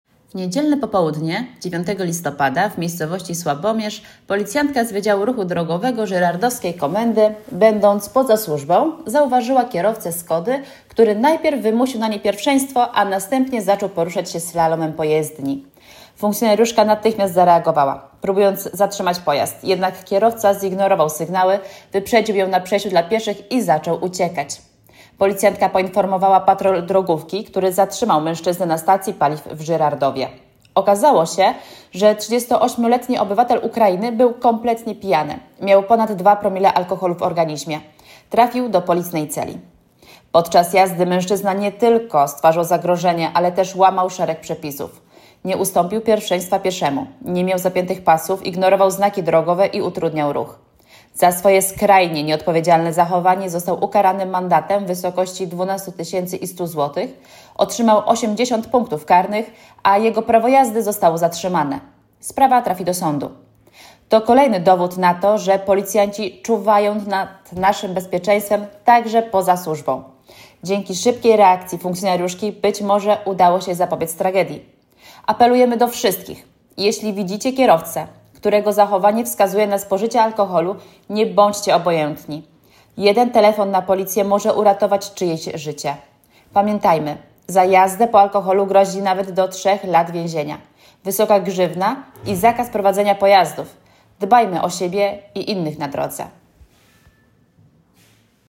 Nagranie audio Wypowiedź